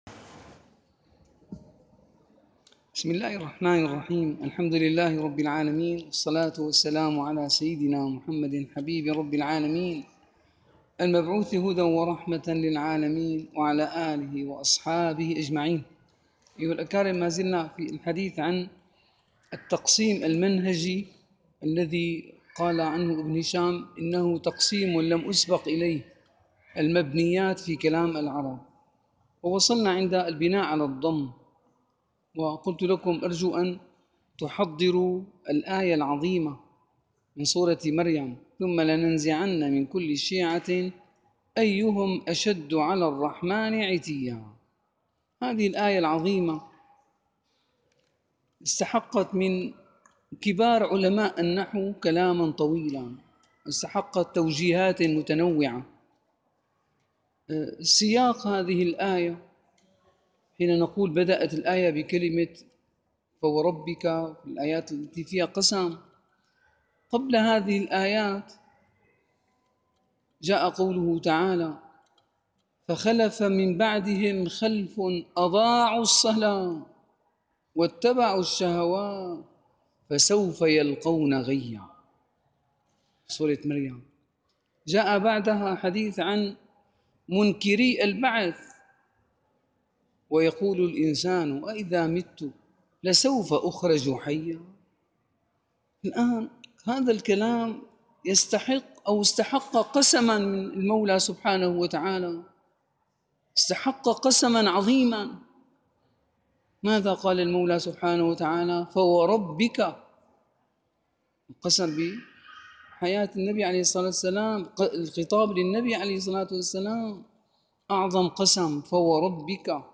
- الدروس العلمية - شرح كتاب شذور الذهب - 29- شرح كتاب شذور الذهب: المبني على الضم 3